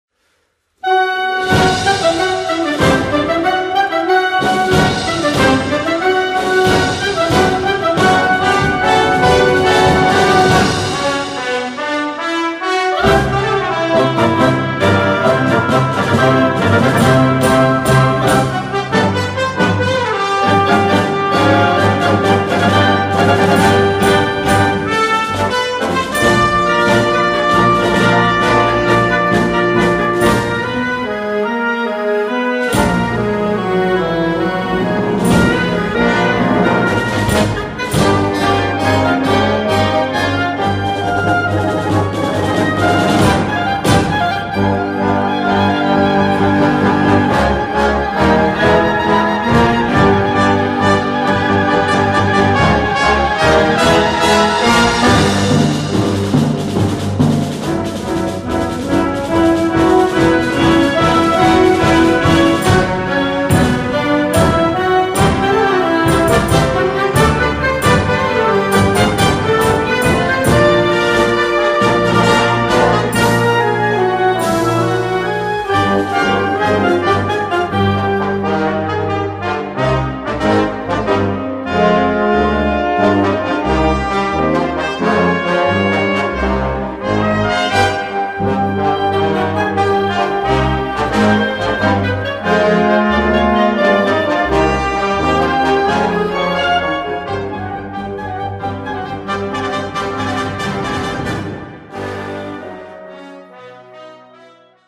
Voicing: Concert Band - Blasorchester - Harmonie